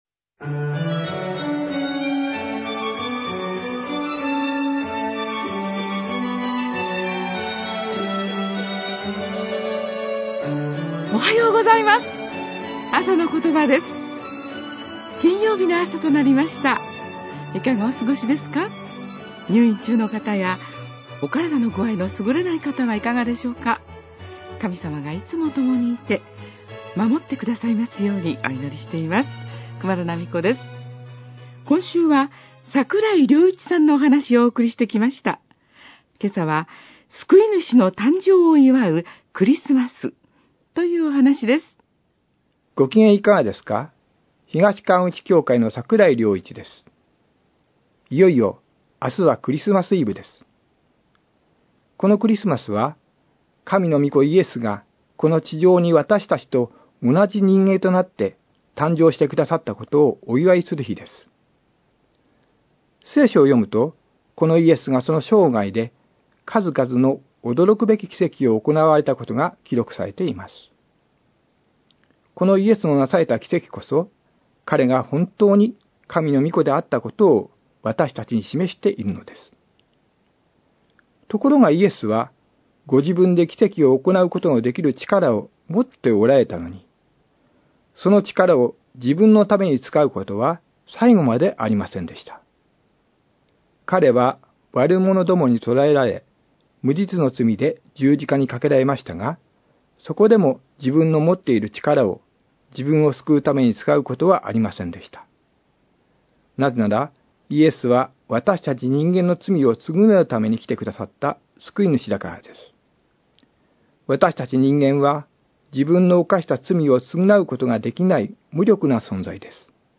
あさのことば 2016年12月23日（金）放送